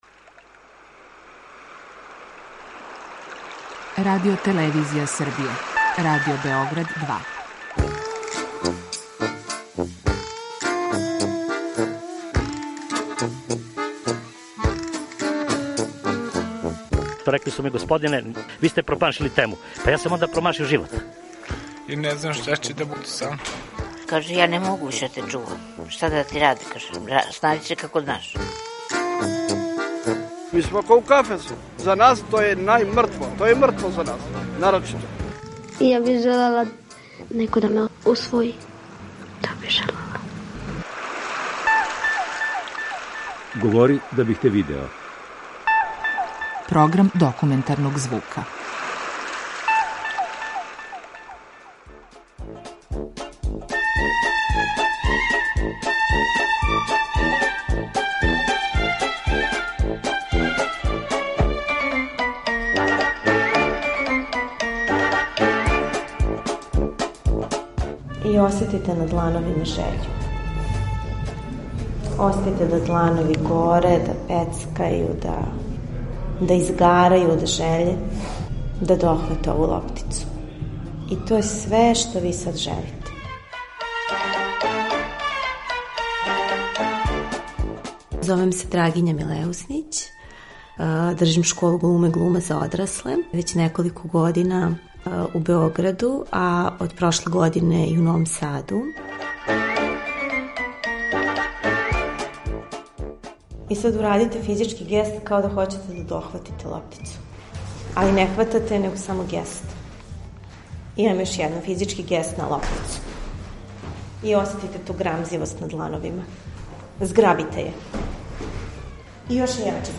Документарни програм
Слушаоци ће такође имати прилике да чују тонске записе са драмске радионице, као и изјаве њених полазника.